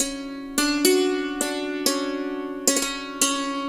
SANTOOR1  -R.wav